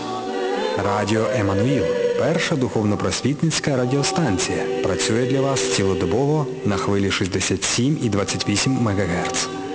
153 Кб 24.01.2010 17:40 Джингл для